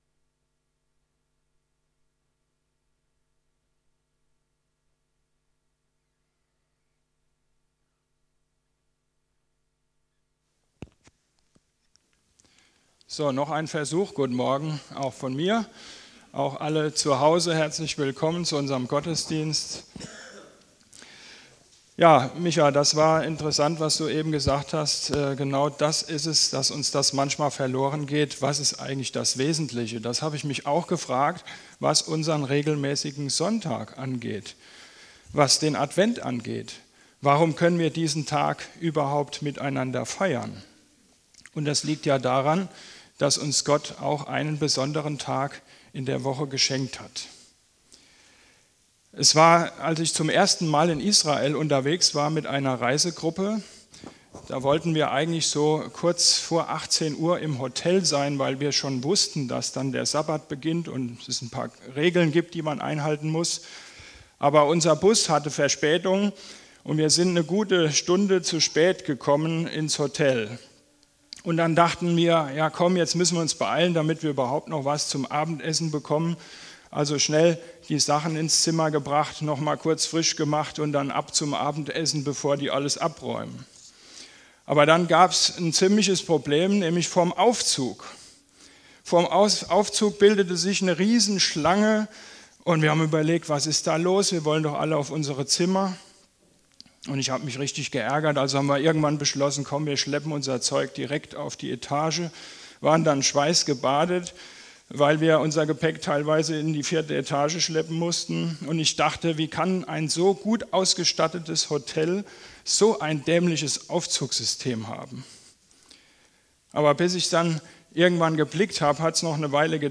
PREDIGTEN - Ev. Gemeinde am Soonwald